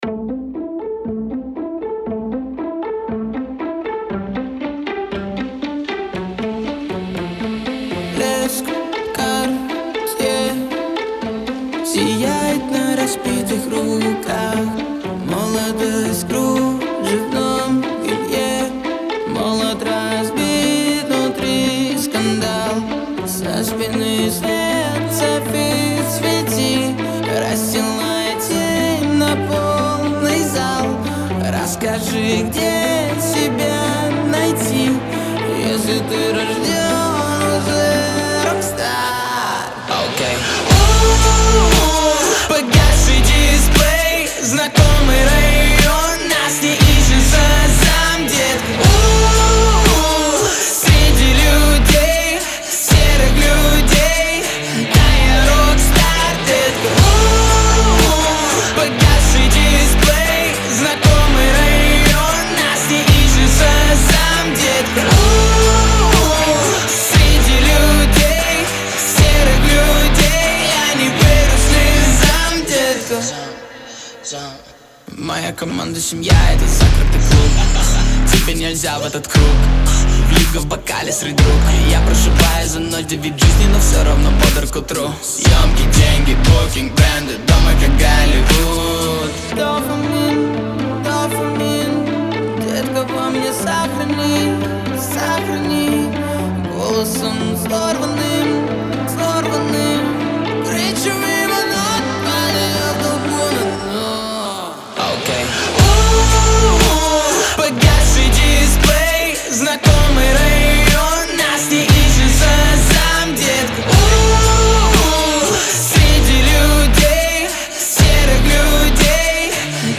это энергичная композиция в жанре поп-рок